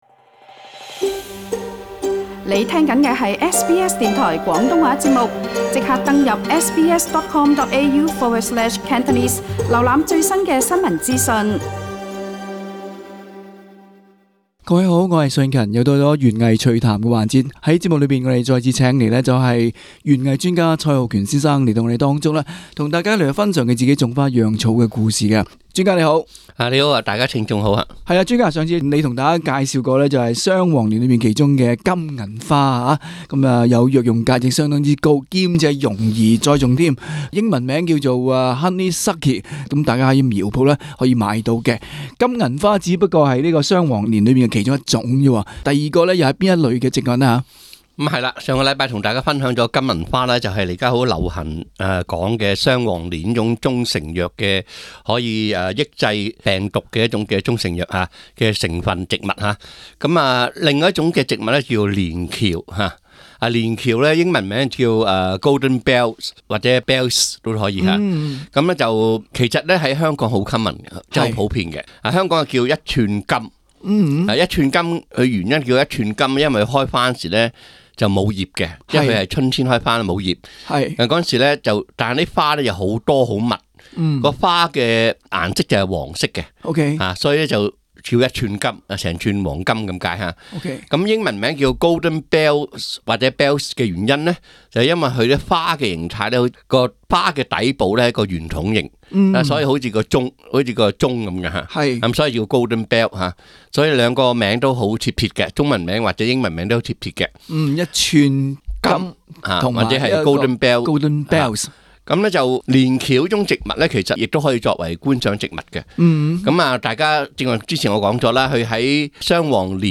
今期【園藝趣談】環節，園藝專家為大家分享談談種植金銀花的心得。